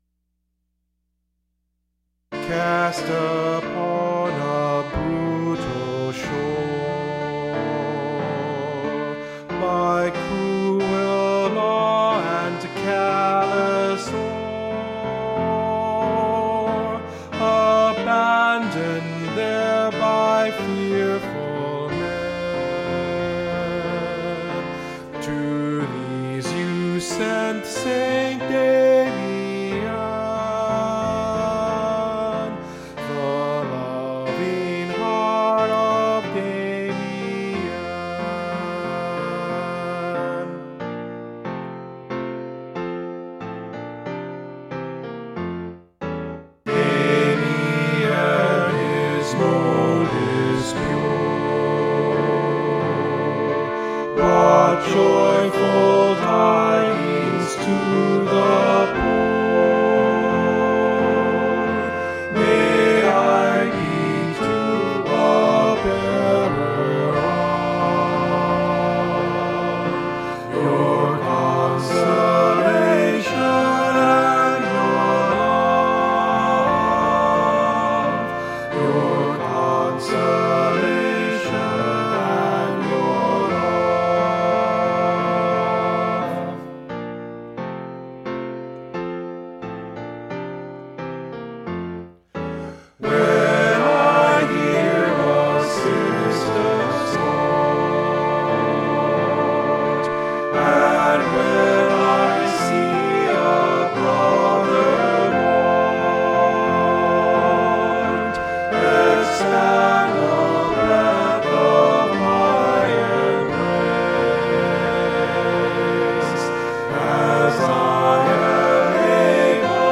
Choir : Eng Desc.